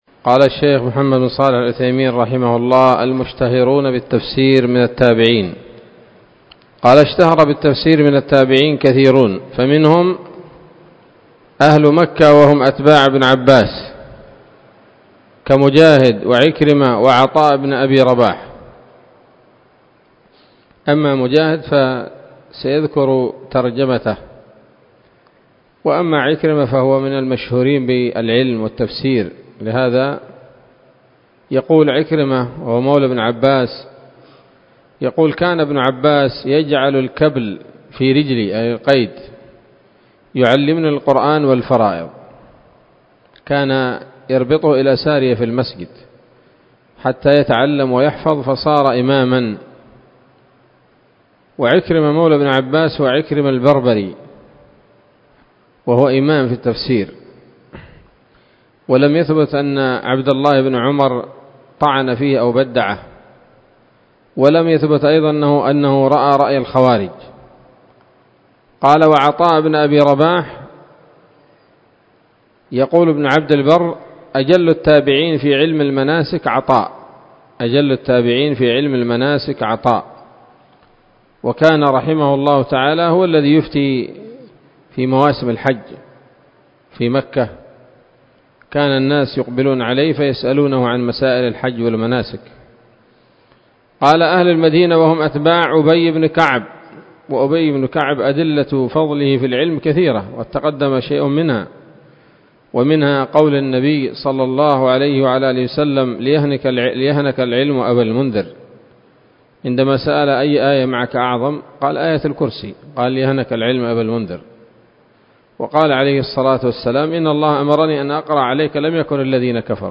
الدرس السادس والعشرون من أصول في التفسير للعلامة العثيمين رحمه الله تعالى 1446 هـ